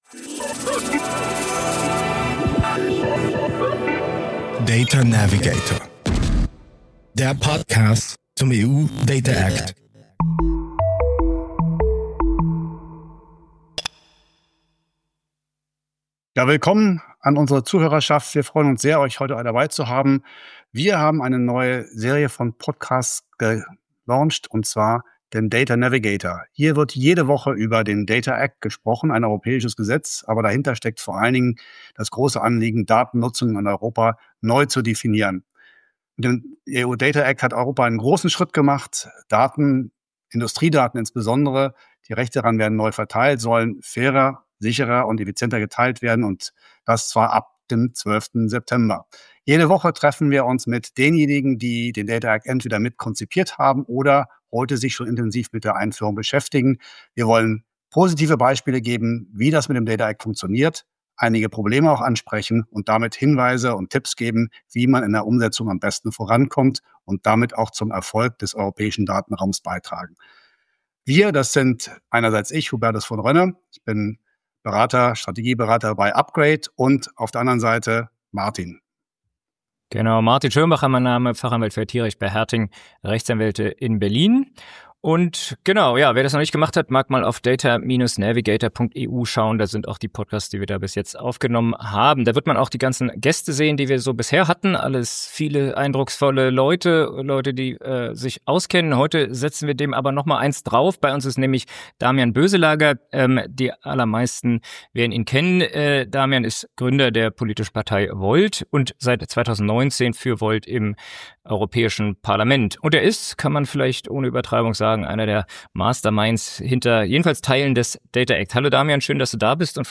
Gast in Folge 13 des Data Navigator Podcasts ist Damian Boeselager, Gründer von VOLT und maßgeblich an der Entstehung des Data Act beteiligt.